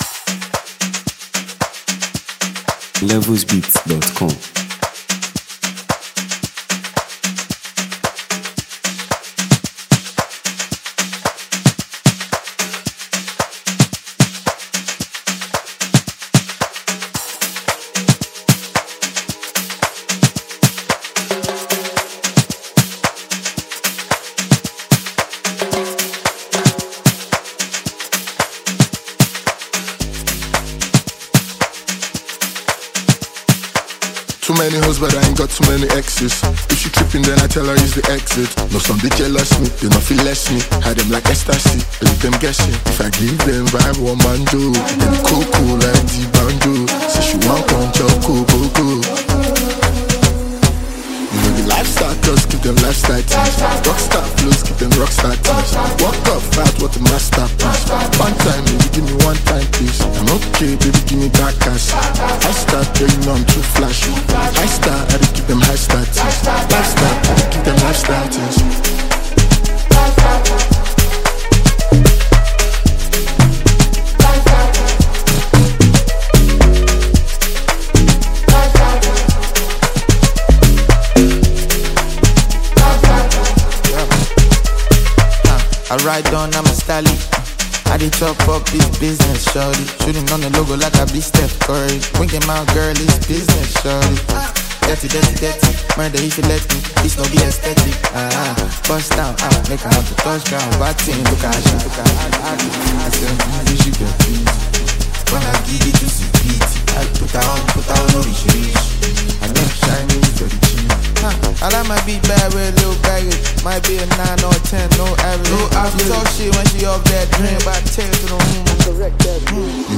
Nigeria Music 2025 2:45
the sensational Nigerian singer-songwriter and performer
the highly gifted Nigerian rapper